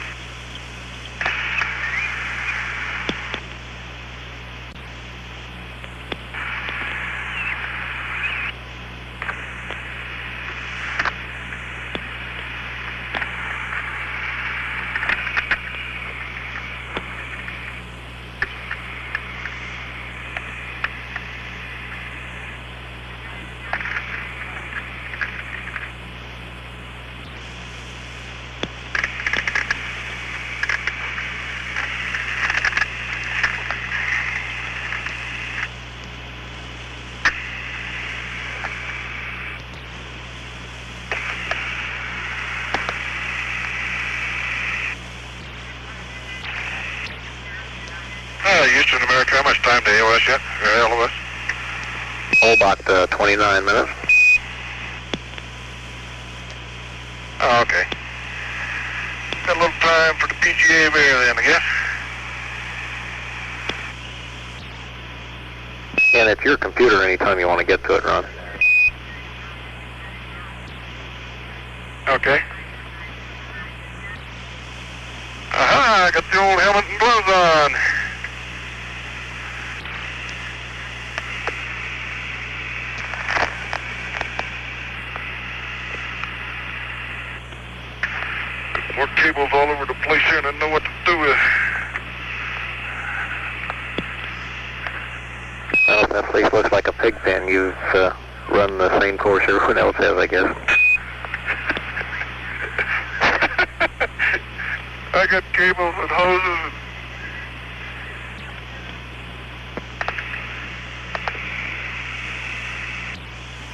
The next audio clip includes about fifty seconds of cabin noise before Ron speaks. He is on VOX (voice-operated switch) but with the sensitivity turned up so that his mike is always live to Earth. There are sounds that may be a camera with an electric motorwind being operated or perhaps a gas valve being opened and closed.